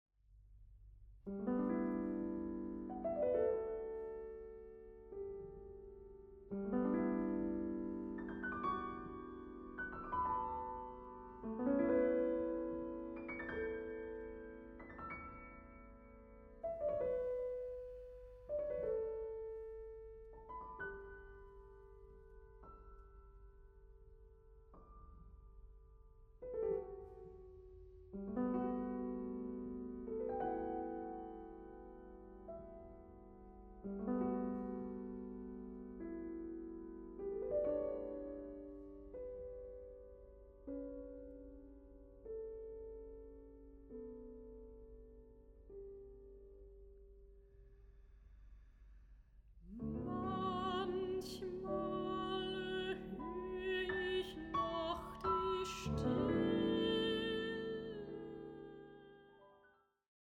Mezzo-soprano
piano
Recording: Mendelssohn-Saal, Gewandhaus Leipzig, 2025